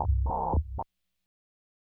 synthFX01.wav